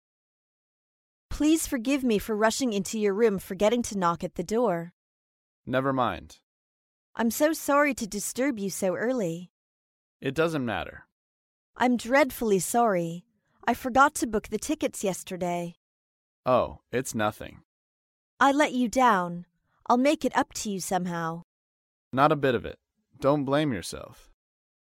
在线英语听力室高频英语口语对话 第120期:做事不妥致歉的听力文件下载,《高频英语口语对话》栏目包含了日常生活中经常使用的英语情景对话，是学习英语口语，能够帮助英语爱好者在听英语对话的过程中，积累英语口语习语知识，提高英语听说水平，并通过栏目中的中英文字幕和音频MP3文件，提高英语语感。